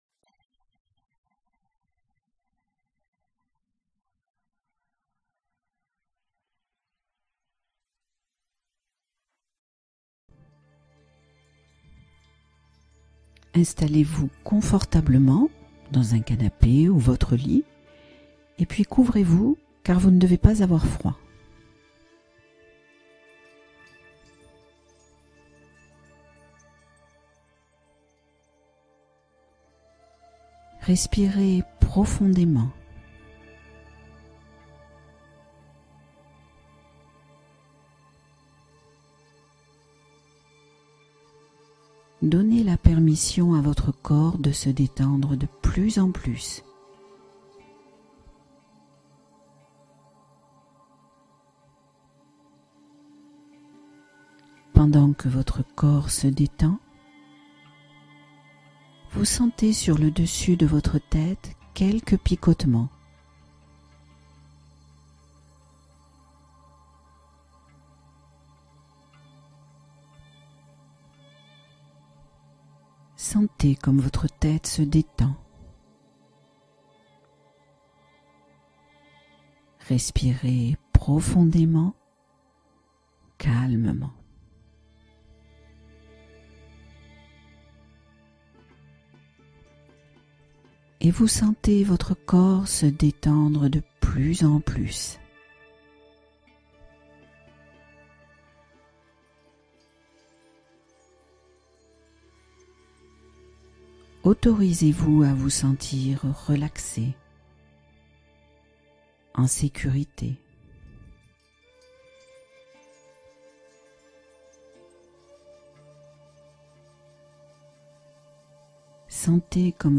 Sommeil apaisant : méditation guidée pour s’orienter vers la détente